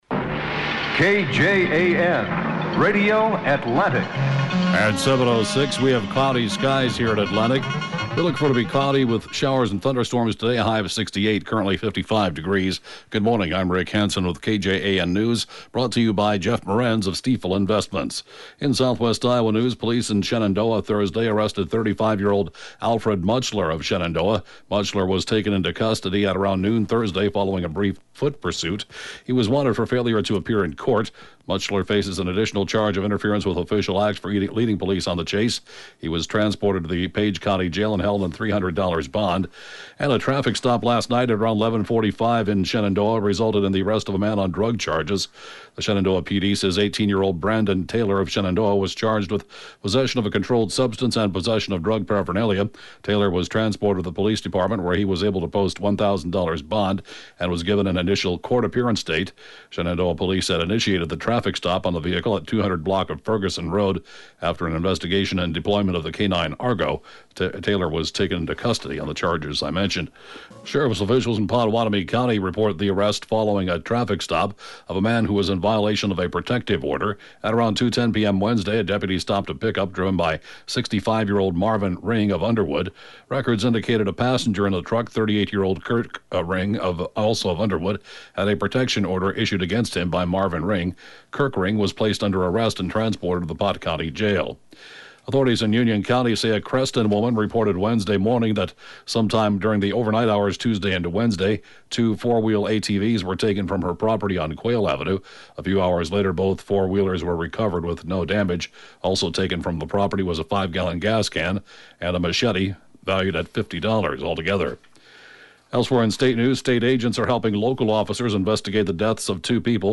(Podcast) KJAN Morning News & funeral report, 3/24/2017